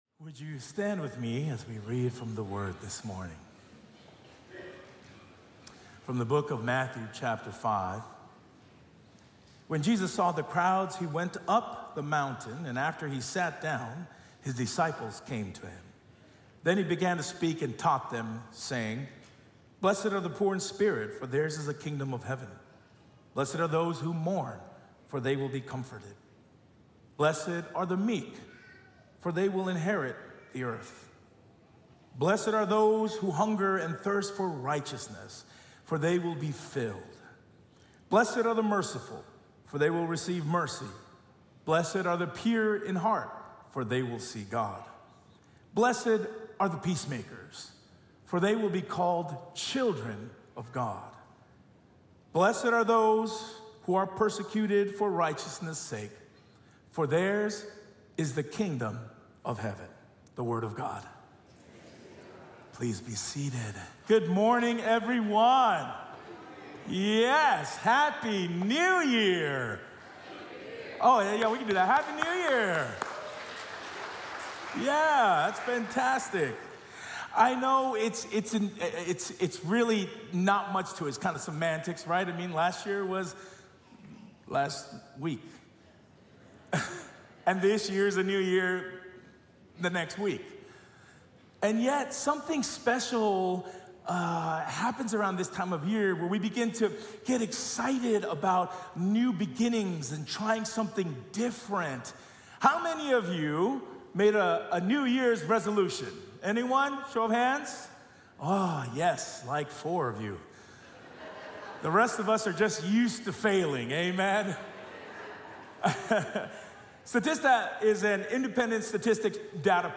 This is the sermon audio podcast for La Sierra University Church.